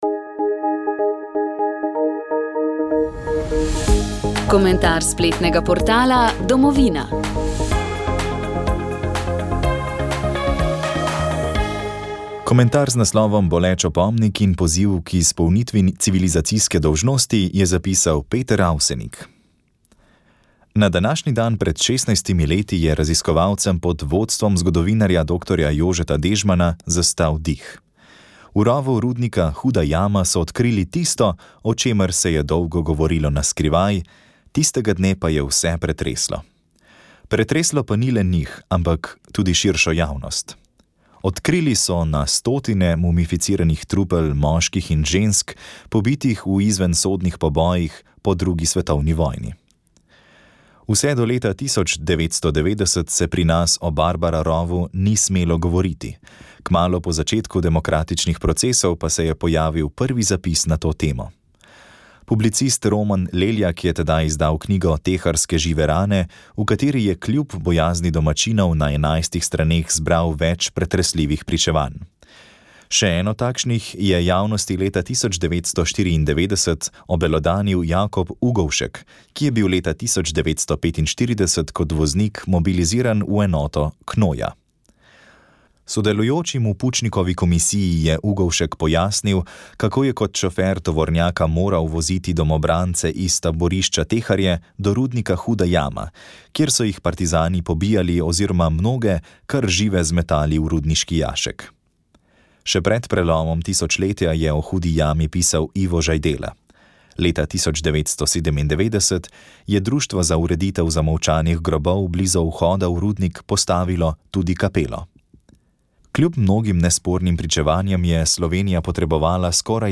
V Trstu v tamkajšnji Peterlinovi dvorani je septembra lani, potekal znanstveni posvet z naslovom Franc Jeza (1916–1984): pisatelj, publicist in borec za samostojno in demokratično Slovenijo. Ob 40-letnici njegove smrti sta ga pripravila Študijski center za narodno spravo in Društvo slovenskih izobražencev iz Trsta.